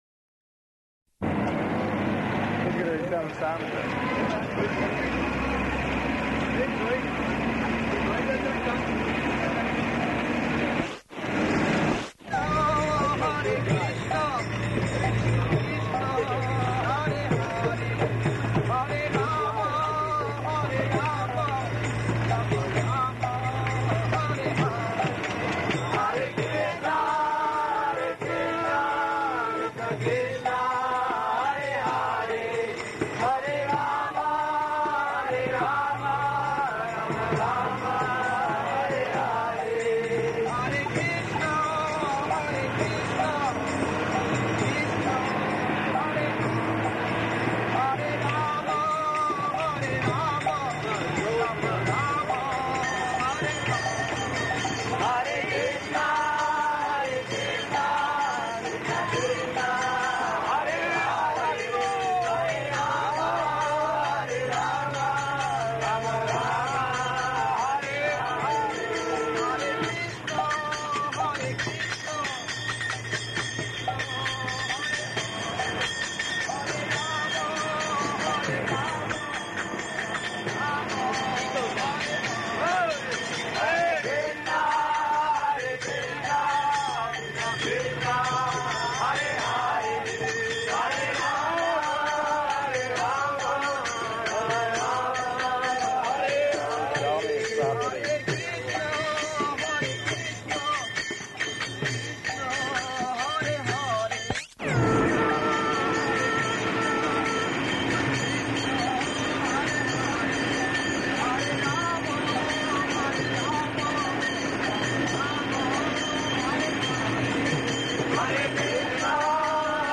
November 28th 1971 Location: Vrindavan Audio file
[in bus, arriving; kīrtana outside]
[much Hindi and obeisances in background] [breaks] Prabhupāda: …doing these same things.